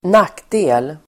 Uttal: [²n'ak:de:l]